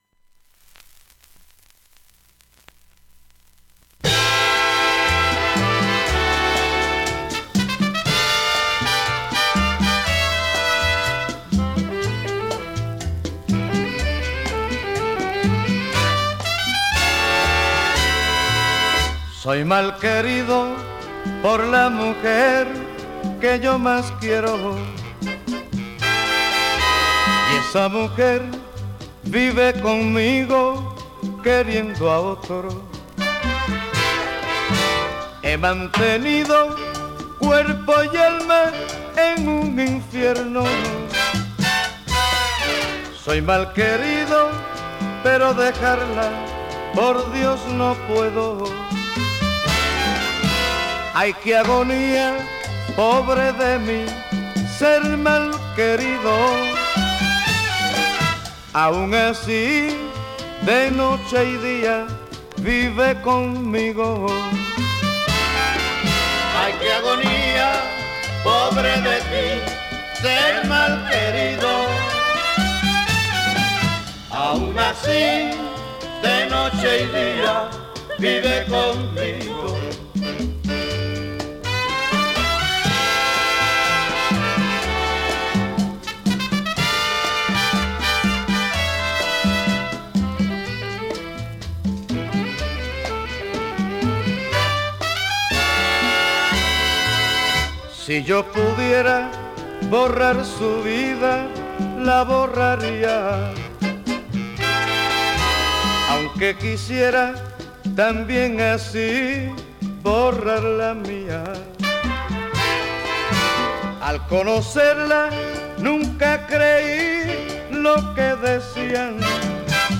ムードボレロラテン
ライトボレロラテン